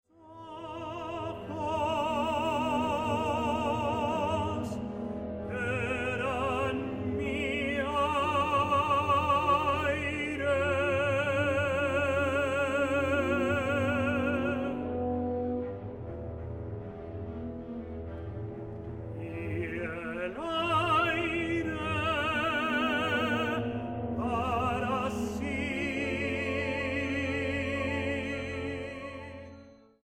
para Coro y Orquesta